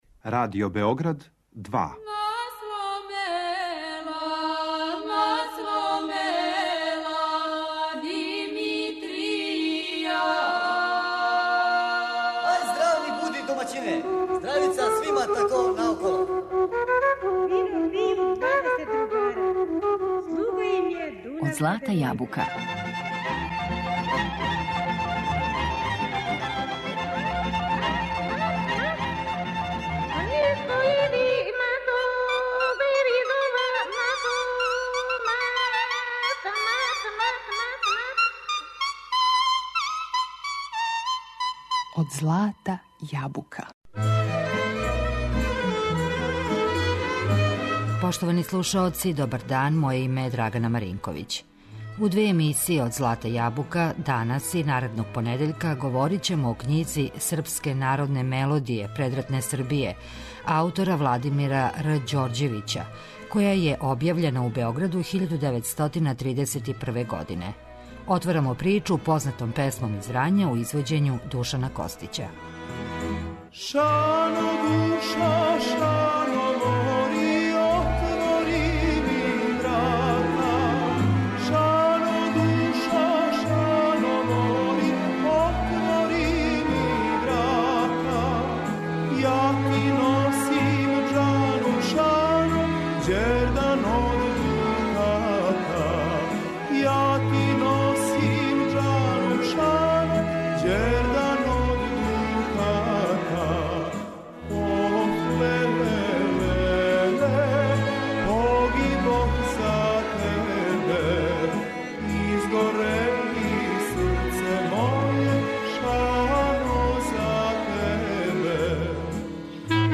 Емисија изворне народне музике